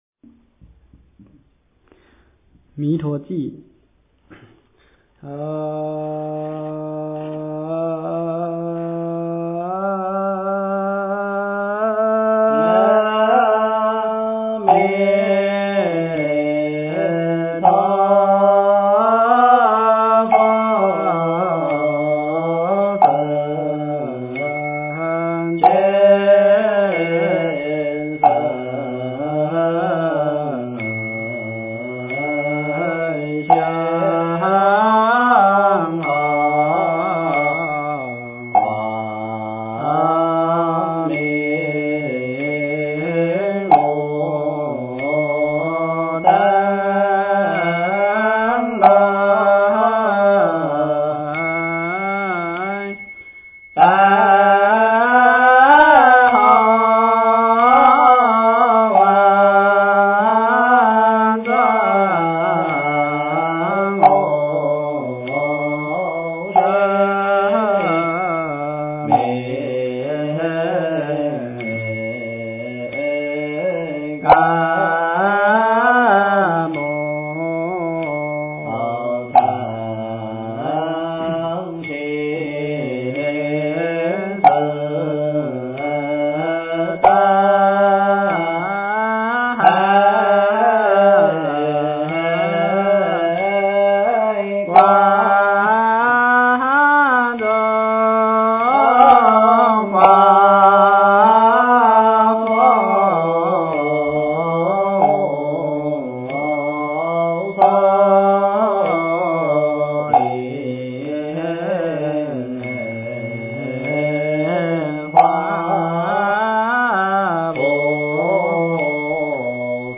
经忏
佛音 经忏 佛教音乐 返回列表 上一篇： 六字大明咒--佛光山梵呗团 下一篇： 一心求忏悔-闽南语--新韵传音 相关文章 《妙法莲华经》药王菩萨本事品第二十三--佚名 《妙法莲华经》药王菩萨本事品第二十三--佚名...